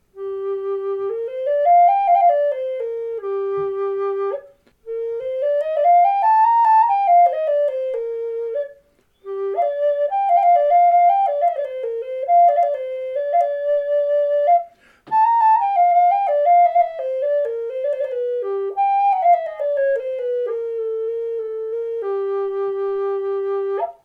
G4 in Wormy Chestnut from Hemphill Road near me, which is where ‘Popcorn’ Sutton grew up.